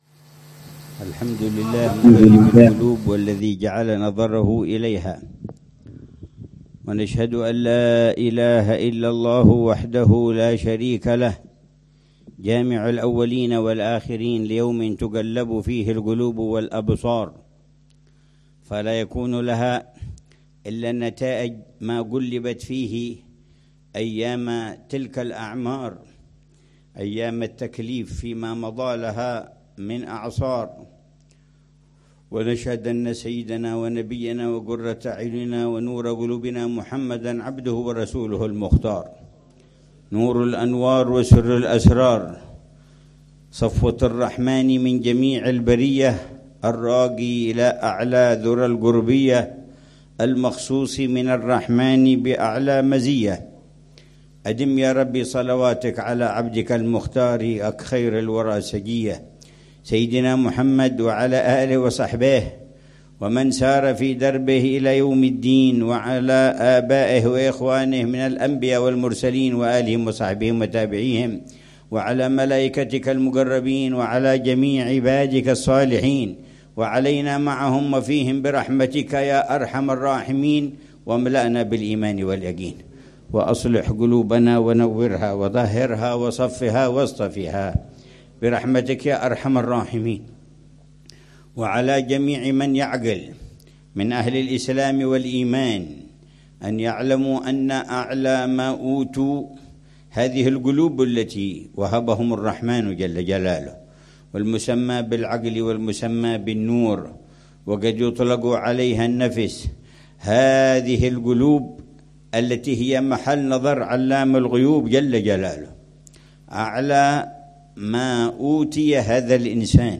محاضرة
عبر اتصال مرئي